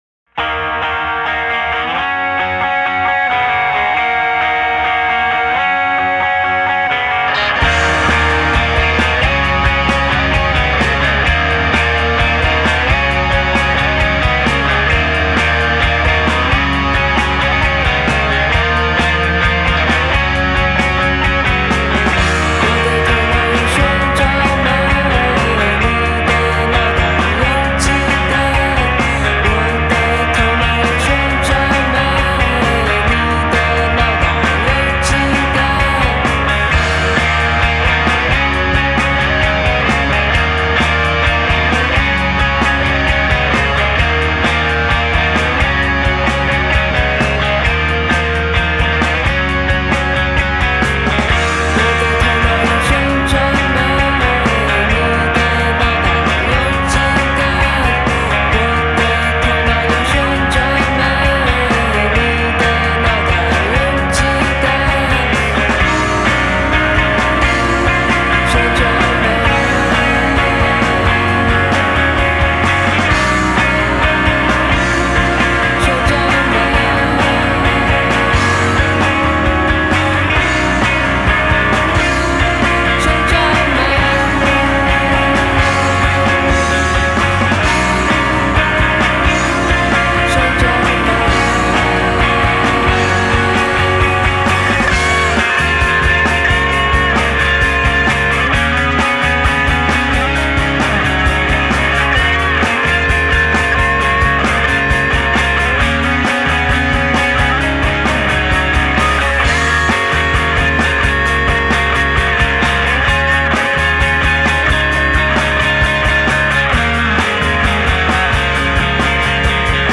在层层的吉他音墙中，铺陈出令人难以忘怀的旋律。
迷幻噪音的吉他音墙，强烈的鼓击，稳定的贝斯声线和时而疏离人声